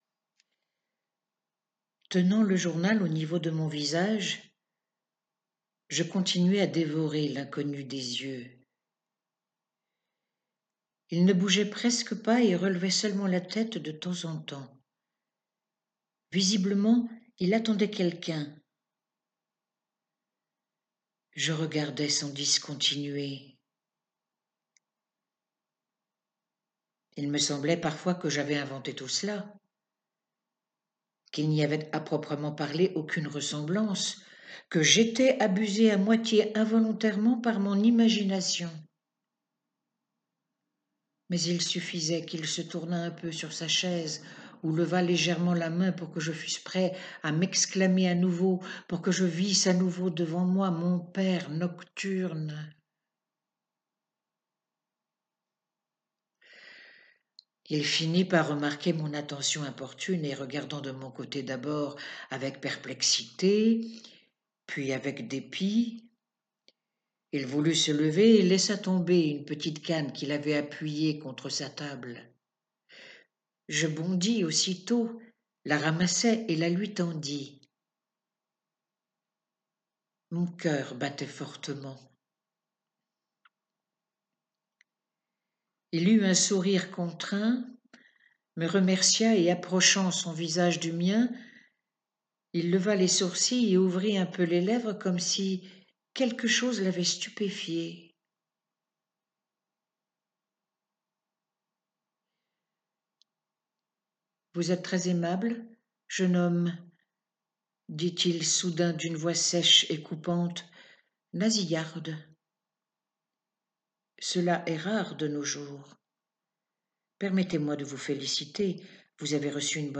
Un rêve de Tourguéniev - Récit lu